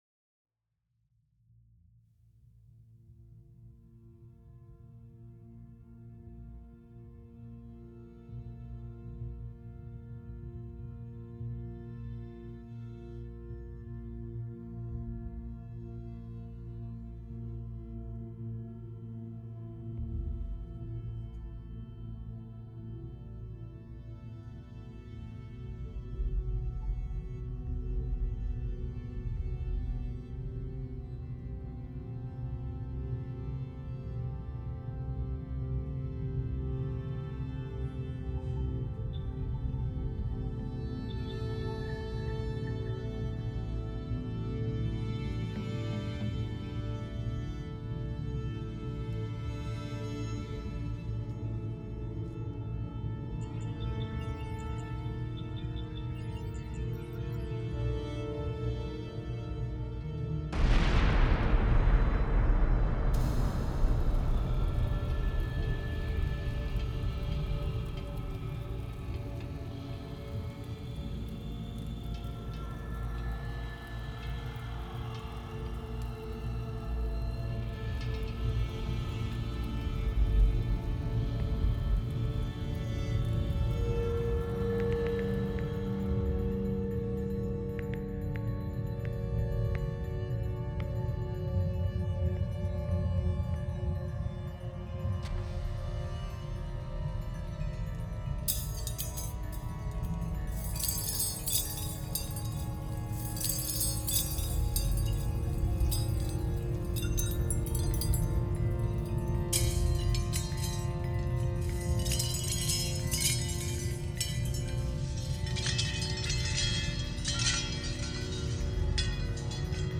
Composition pour : Percussions, Tarang, Bruits, clusters et autres sons. Empédocle aurait mis fin à son existence en se jetant dans l'Etna!